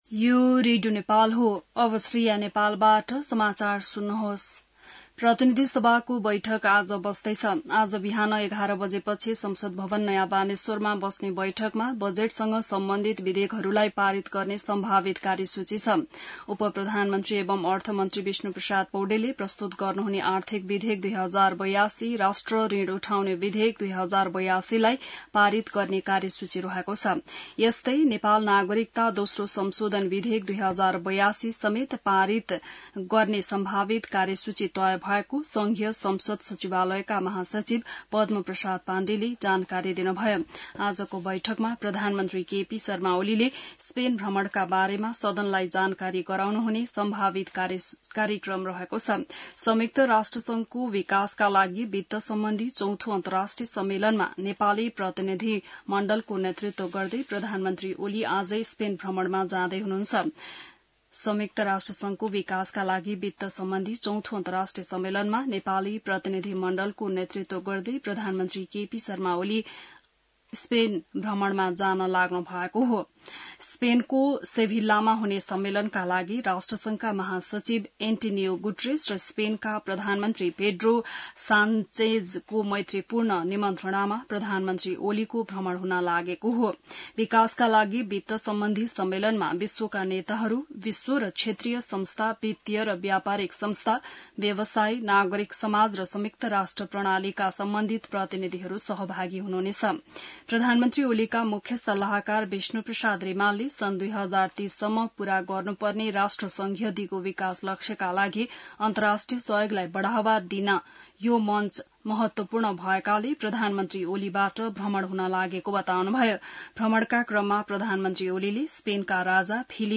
बिहान ६ बजेको नेपाली समाचार : १४ असार , २०८२